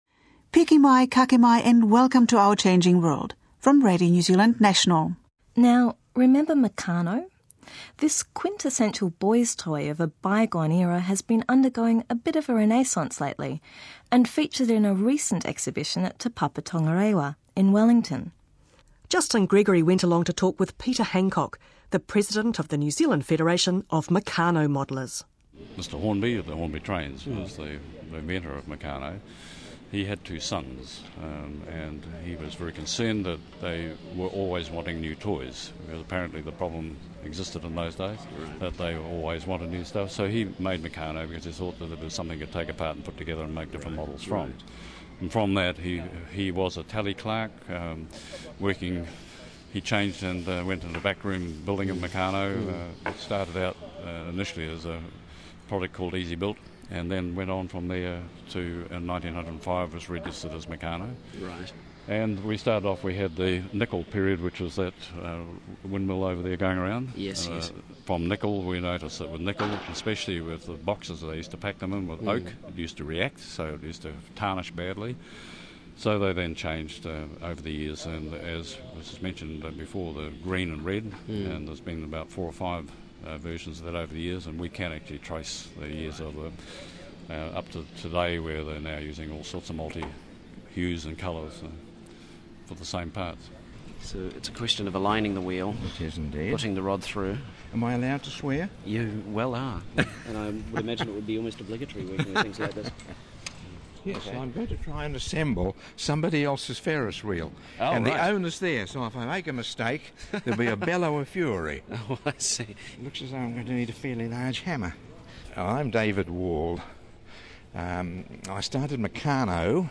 Click here to listen to an MP3 Podcast by Radio NZ of interviews at Te Papa on Saturday 20th November (22 minutes).